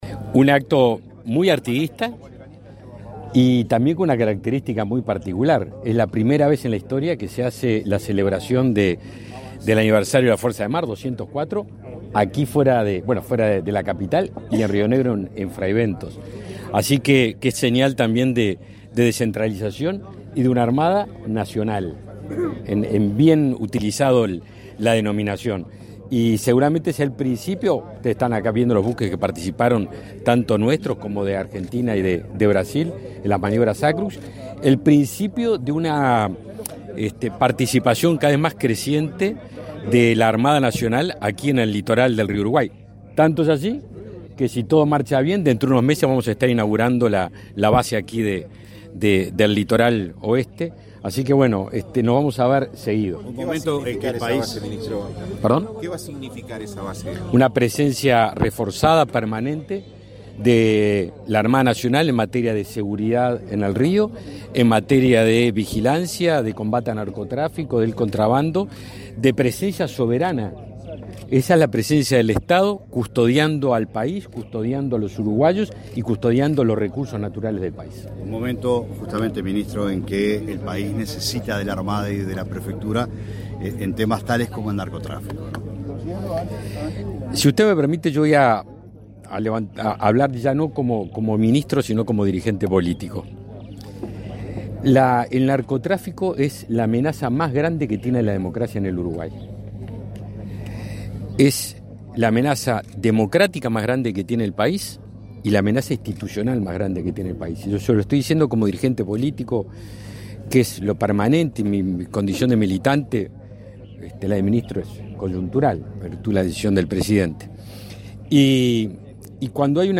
Declaraciones del ministro de Defensa Nacional
Luego, dialogó con la prensa.